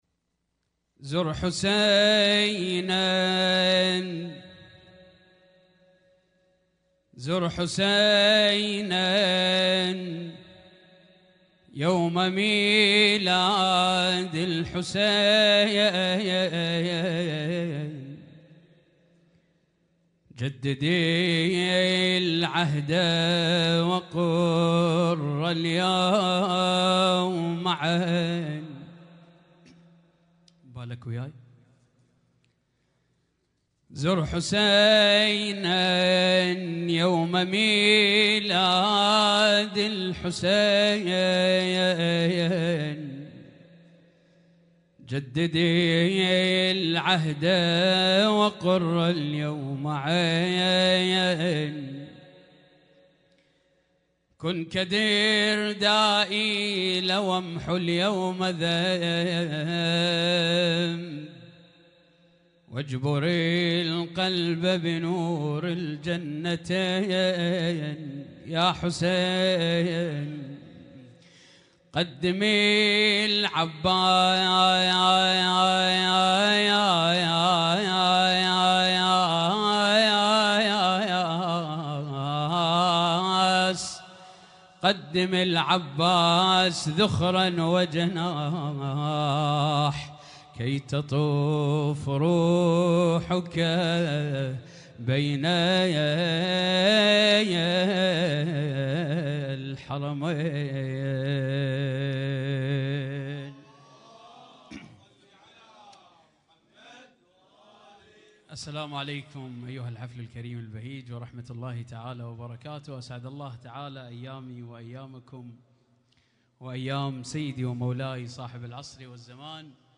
Husainyt Alnoor Rumaithiya Kuwait
اسم النشيد:: مولد الأقمار الثلاثة عليهم السلام
الرادود